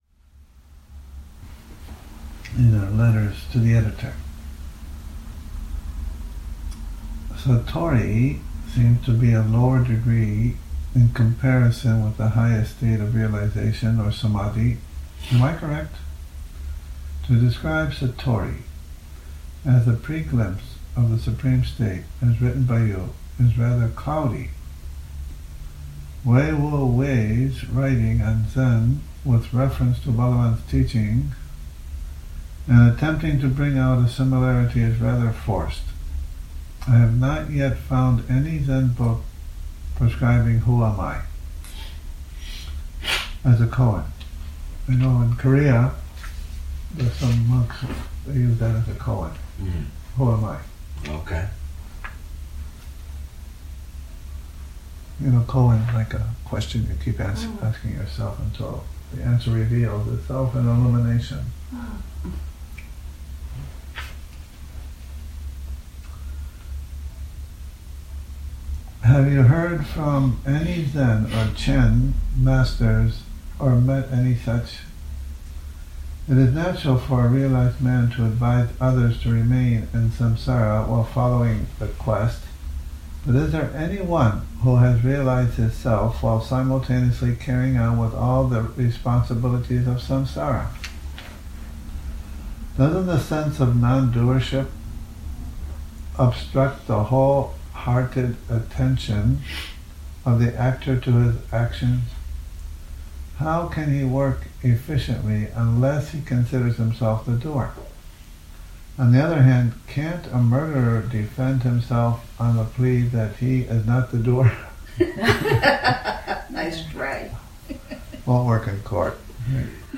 Morning Reading, 09 Oct 2019
a reading from 'The Mountain Path' Morning Reading, 09 Oct 2019